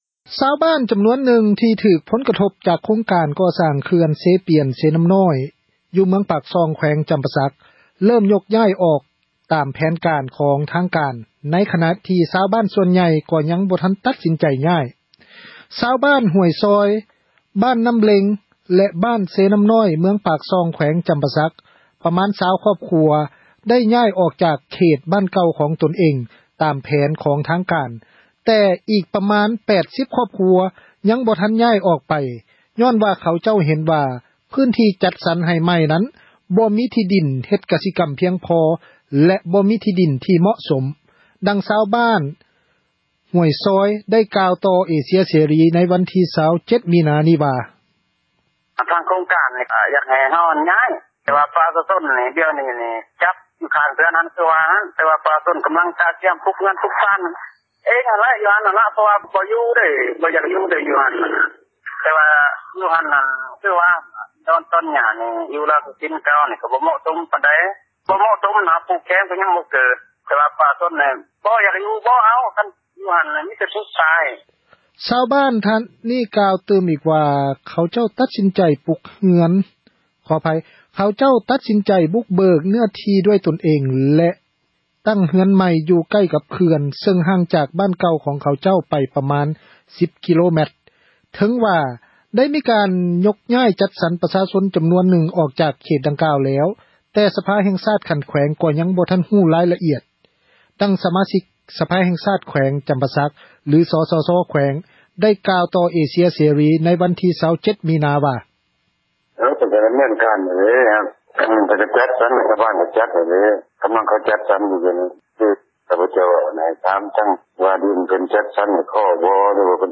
ຊາວບ້ານຫ້ວຍຊອຍ, ບ້ານນ້ຳເລງ, ແລະ ບ້ານເຊນ້ຳນ້ອຍ ເມືອງປາກຊ່ອງ ແຂວງຈຳປາສັກ ປະມານ 20 ຄອບຄົວ ໄດ້ຍ້າຍອອກຈາກ ເຂດບ້ານເກົ່າ ຂອງພວກຕົນ ຕາມແຜນ ຂອງທາງການ, ແຕ່ມີອີກປະມານ 80 ຄອບຄົວ ຍັງບໍ່ທັນຍ້າຍ ອອກໄປ ຍ້ອນເຂົາເຈົ້າເຫັນວ່າ ພື້ນທີ່ຈັດສັນໃໝ່ນັ້ນ ບໍ່ມີທີ່ດິນ ເຮັດກະສິກັມ ພຽງພໍ ແລະ ບໍ່ເໝາະສົມ. ດັ່ງຊາວບ້ານ ບ້ານຫ້ວຍຊອຍ ໄດ້ກ່າວຕໍ່ ເອເຊັຽເສຣີ ເມື່ອວັນທີ 27 ມິນາ ນີ້ວ່າ: